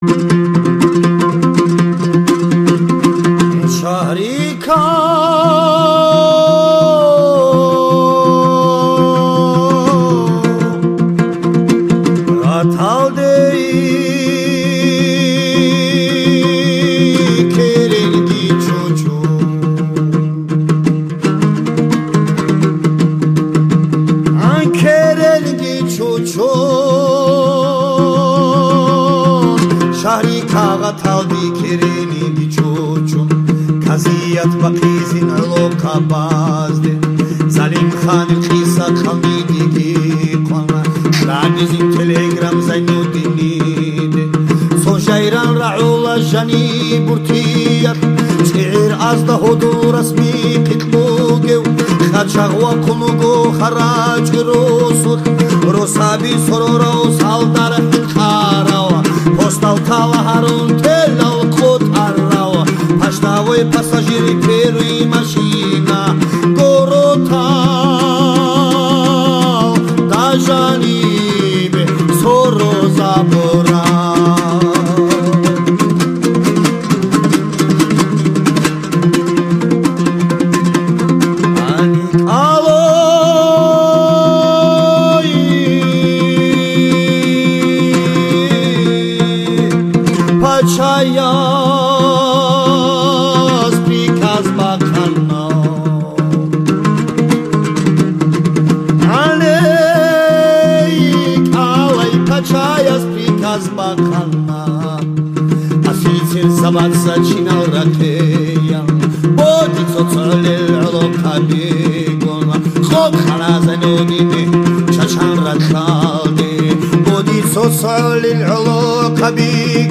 Аварская песня "Абрек Залимхан" (исп.